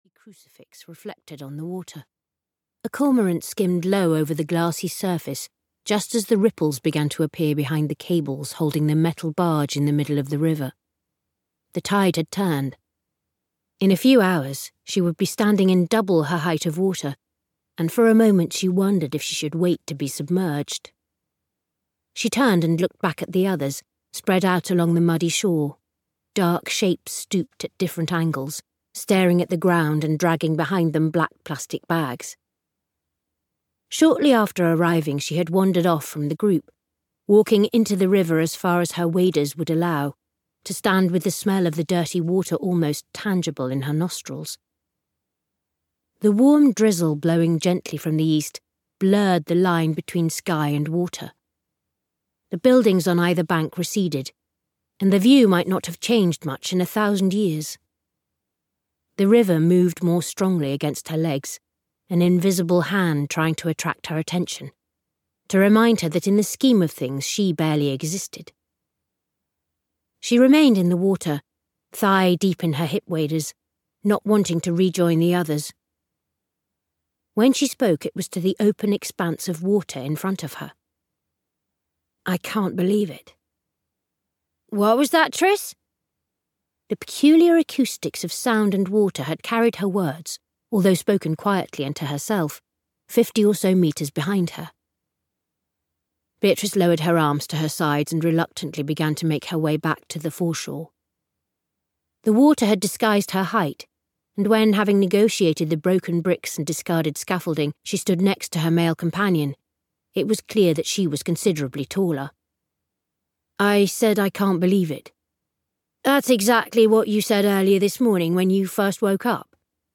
Audiobook A Girl Called Flotsam, written by John Tagholm.
Ukázka z knihy